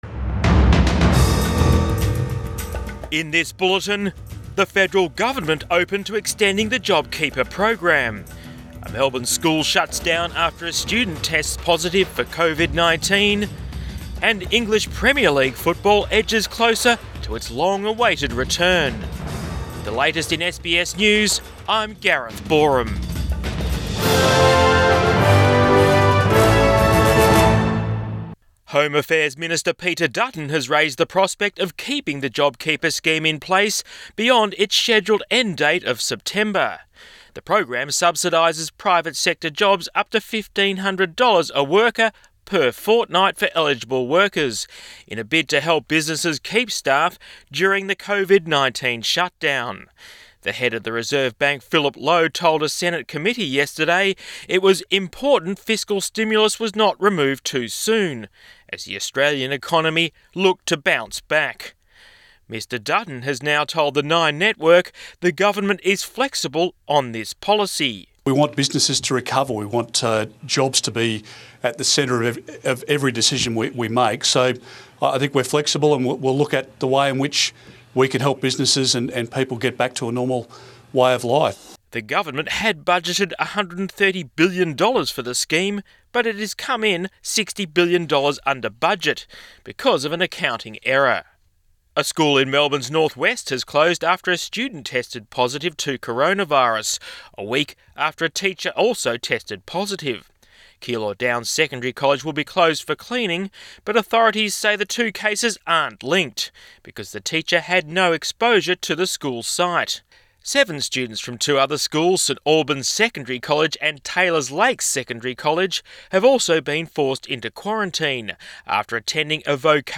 Midday bulletin 29 May 2020